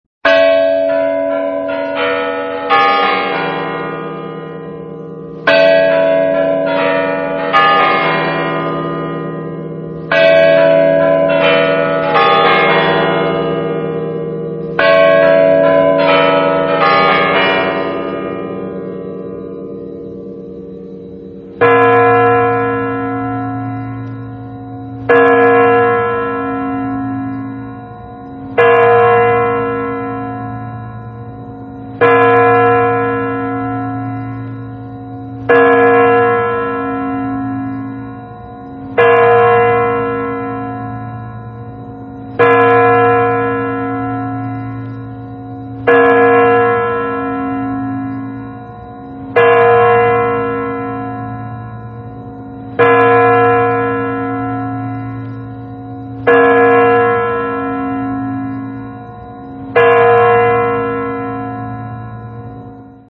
Kremlin chimes - Кремлевские куранты
Отличного качества, без посторонних шумов.
166_kuranty.mp3